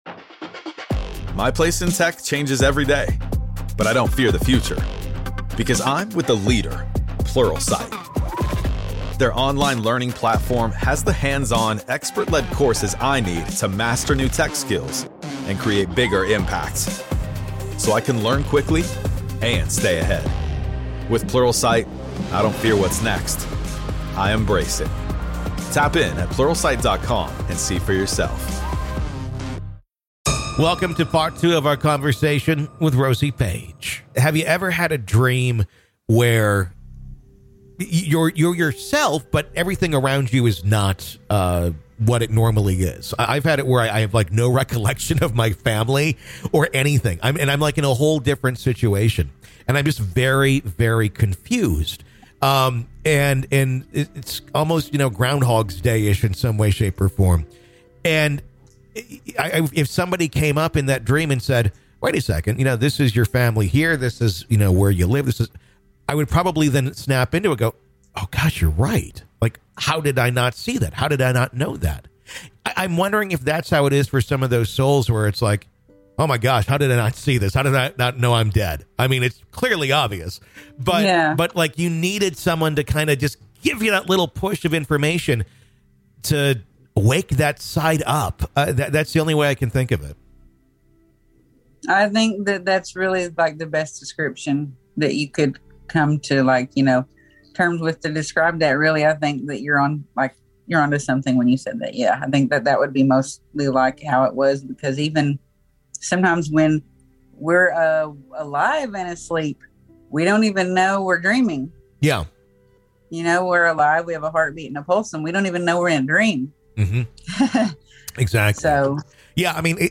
This is Part Two of our conversation.